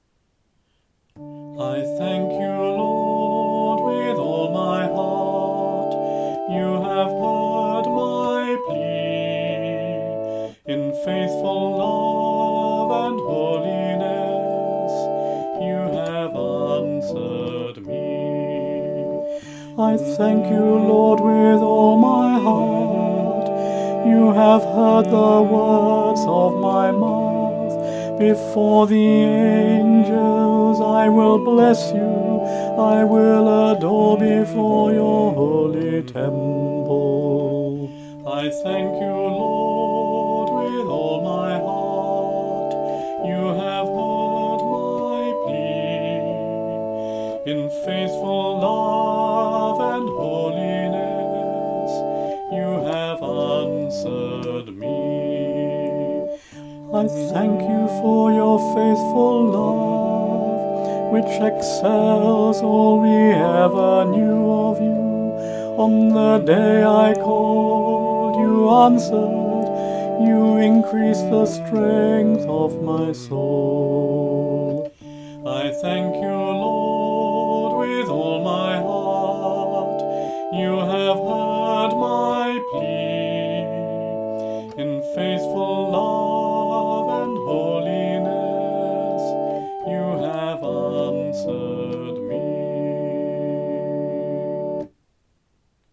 A demo recording will be found here.